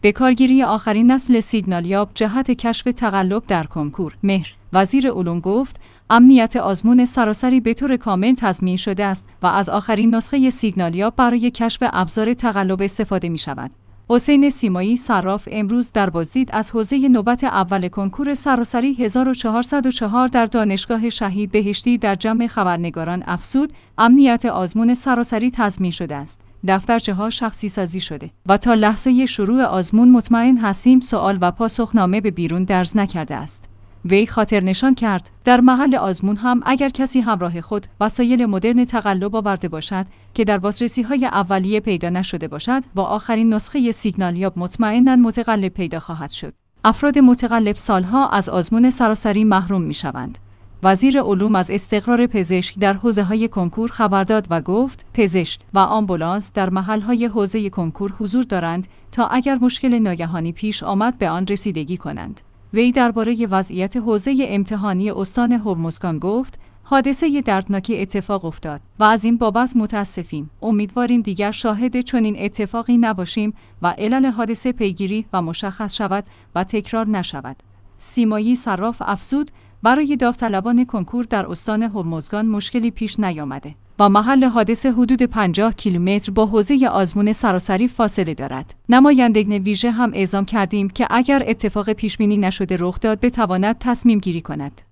حسین سیمایی صراف امروز در بازدید از حوزه نوبت اول کنکور سراسری ۱۴۰۴ در دانشگاه شهید بهشتی در جمع خبرنگاران افزود: امنیت آزمون سراسری تضمین شده است.